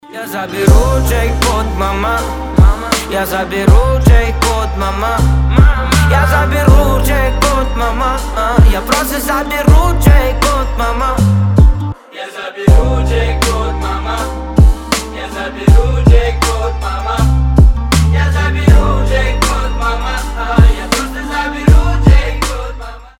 Хип-хоп